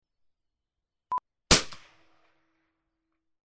308 Bolt action Rifle, Single gun Shot 2 (sound fx)
308 bolt action rifle single gun shot. Echo, reverberant sound. Weapon Sound Effects, Gun shots
308_RifleShots-02_plip.mp3